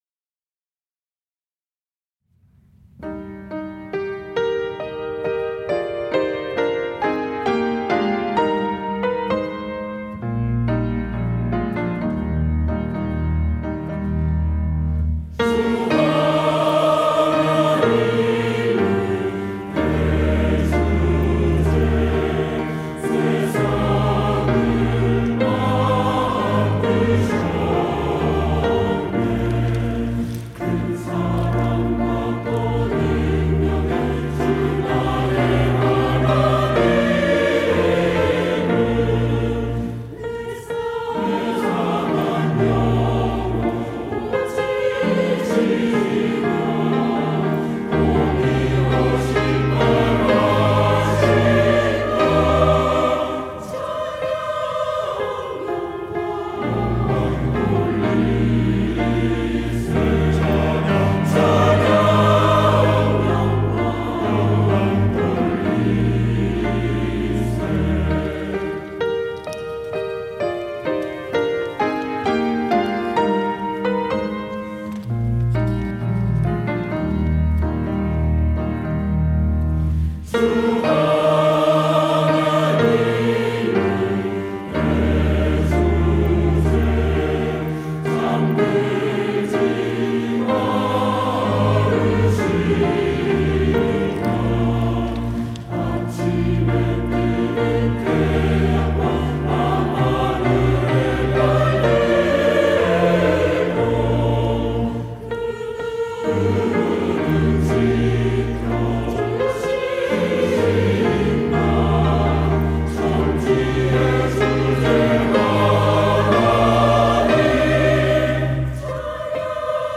시온(주일1부) - 노래로 하나님께 찬양
찬양대